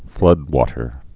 (flŭdtər, -wŏtər)